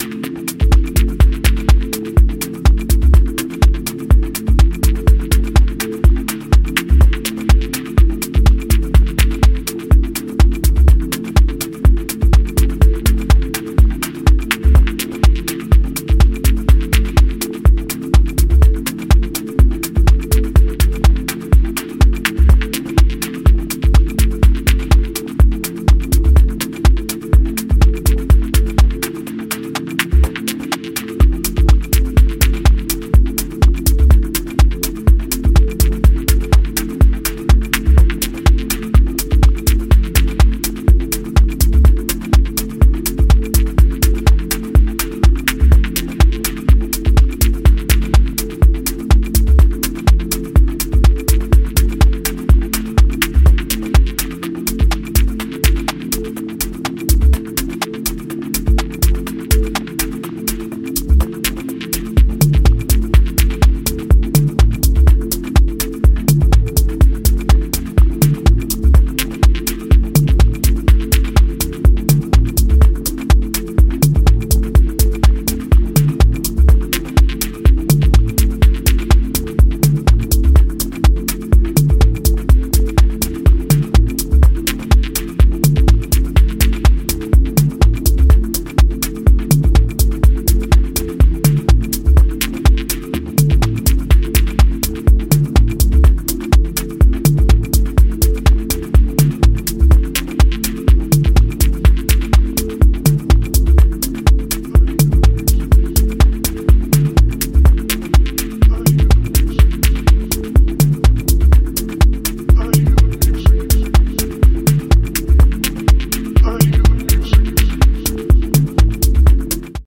serious head nodding beats